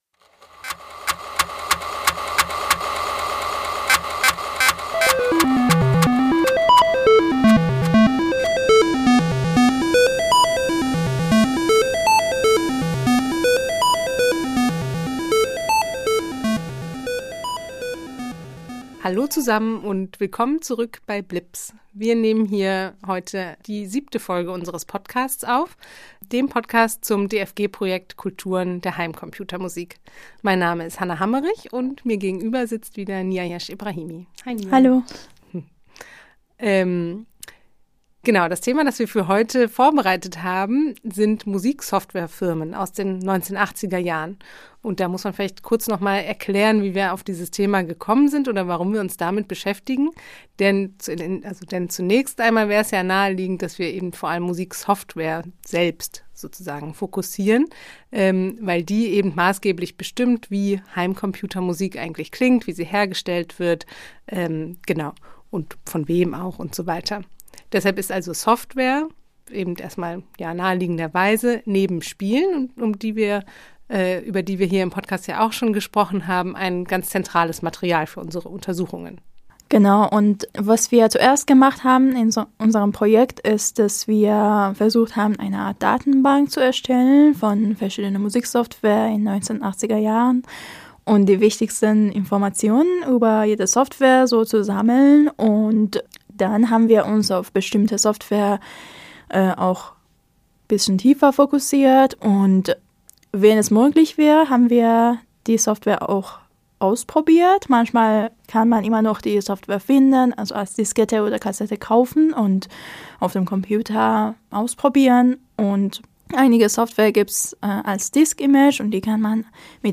Ehemalige Mitarbeiter erzählen uns ihre Geschichte und erklären, welche Potentiale sie im Musikmachen mit dem (Heim-)computer gesehen haben.